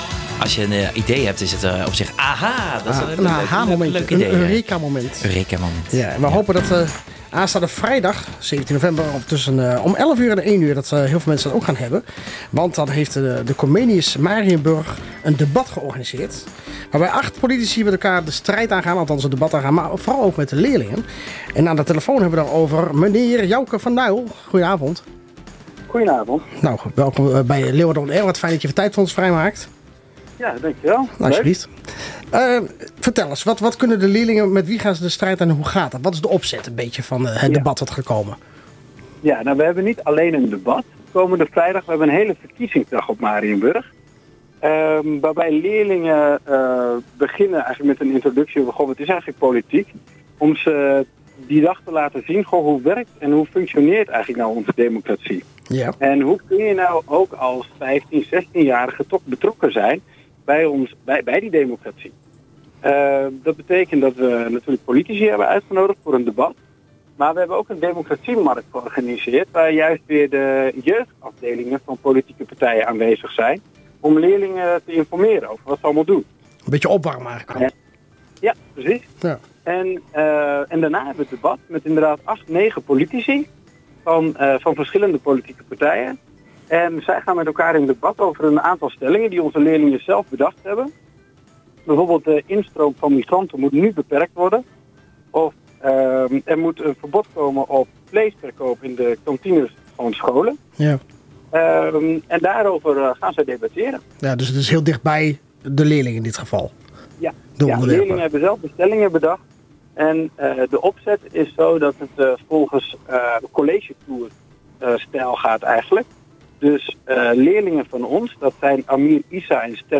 gesprek